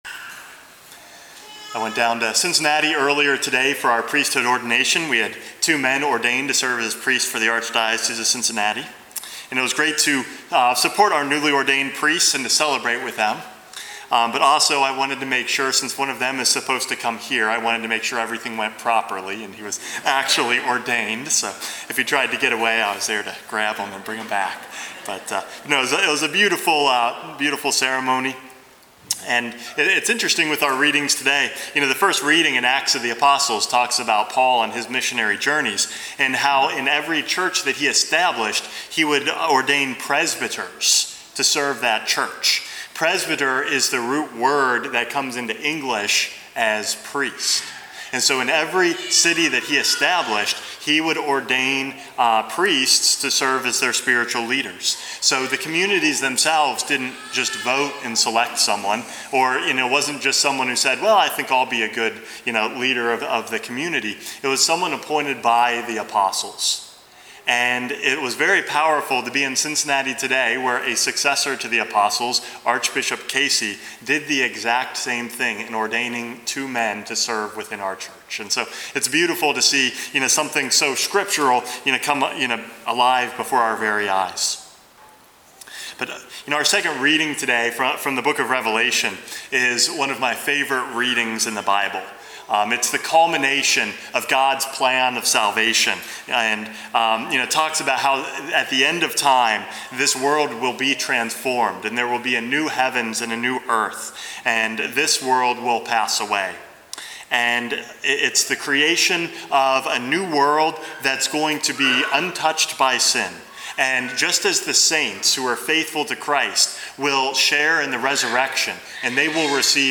Homily #450 - All Things New